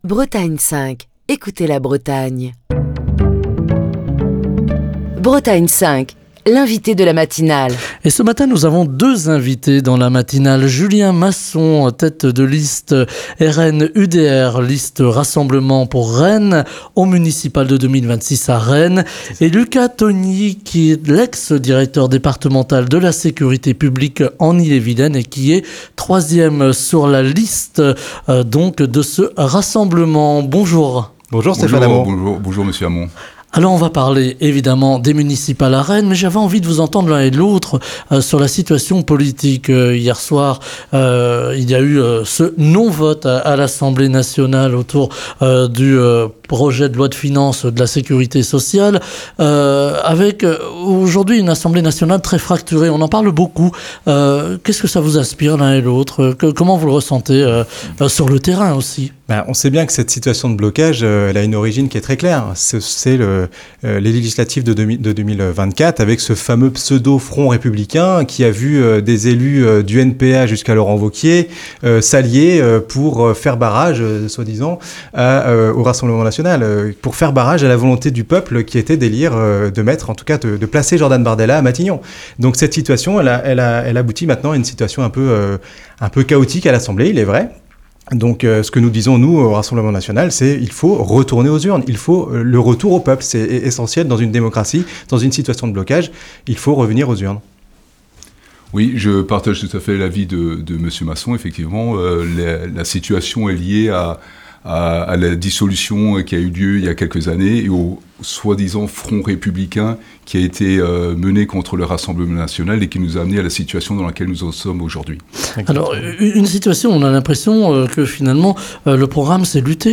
étaient les invités politiques de la matinale de Bretagne 5.